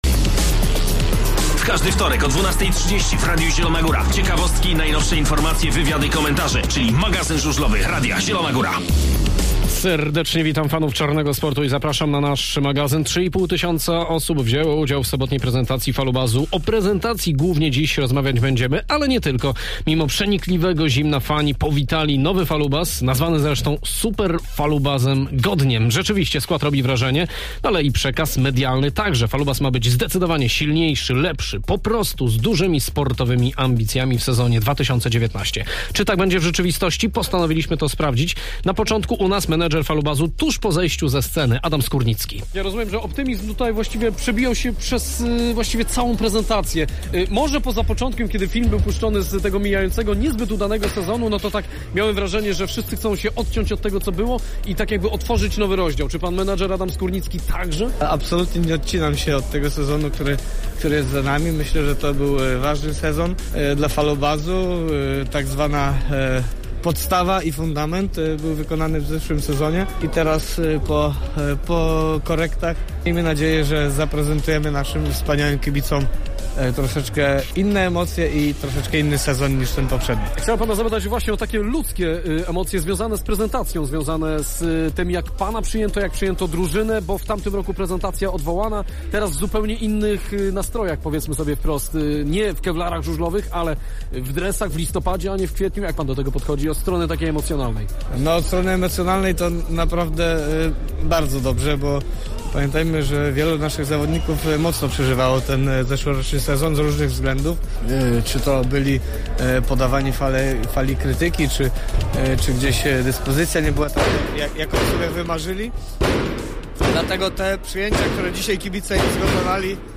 Posłuchajcie rozmów, które przygotowaliśmy dla Was. W programie również rozmowa z naszym ekspertem oraz wizyta w Gorzowie